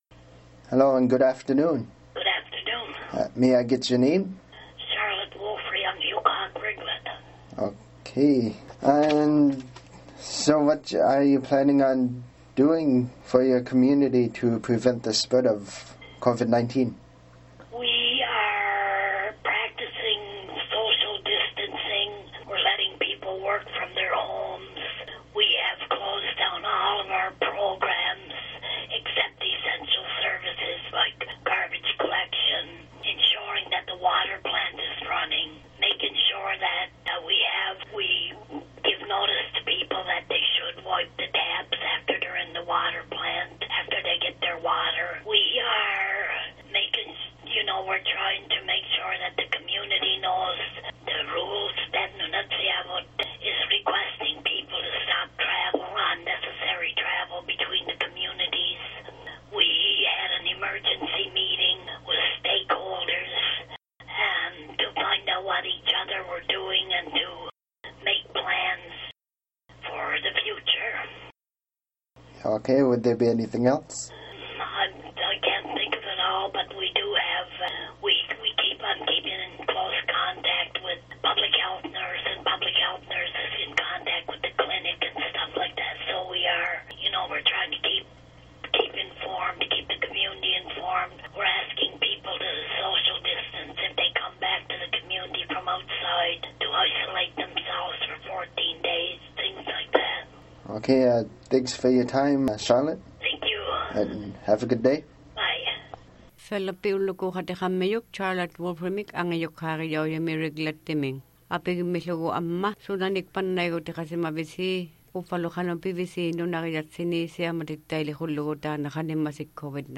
OKâlaKatiget Radio contacted all the angajukKât along the North Coast to see what their plans are for their community members and staff for safety precautions.